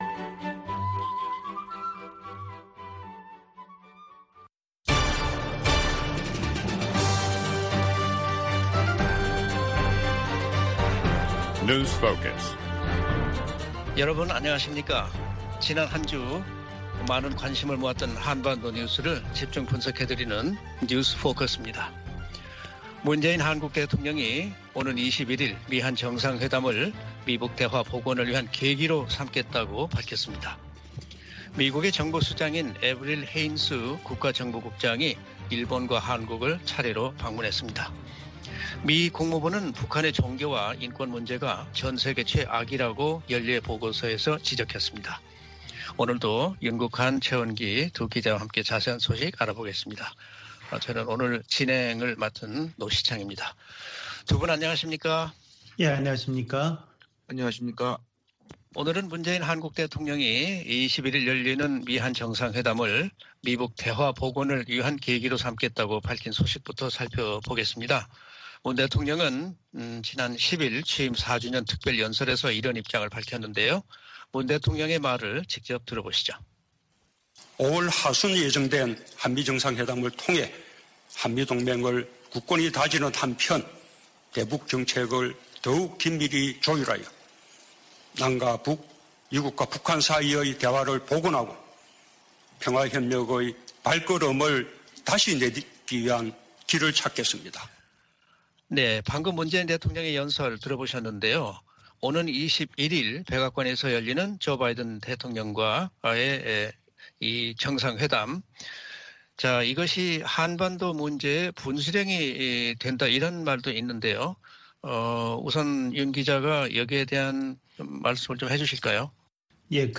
VOA 한국어 방송의 월요일 오전 프로그램 2부입니다.